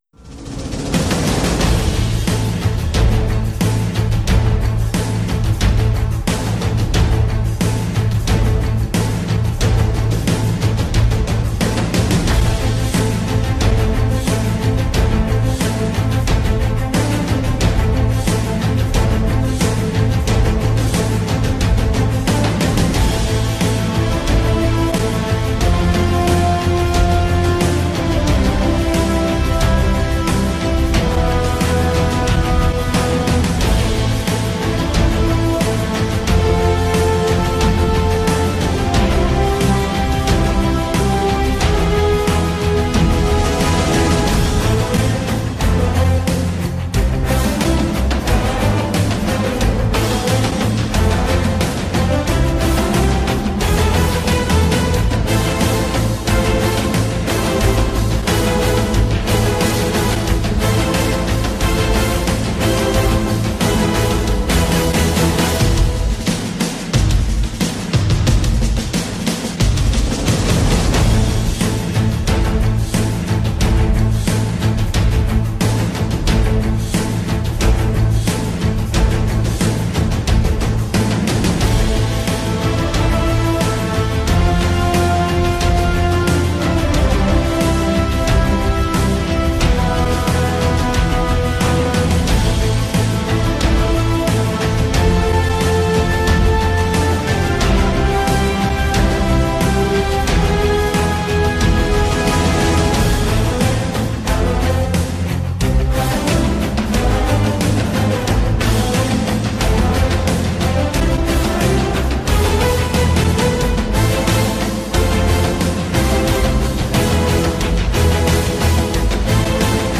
and the "Forgotten Decades" music mix of lesser-played and/or misremembered songs from the 50s through the 90s.